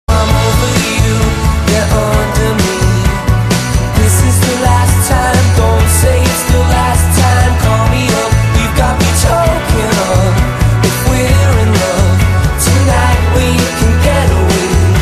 M4R铃声, MP3铃声, 欧美歌曲 109 首发日期：2018-05-14 12:34 星期一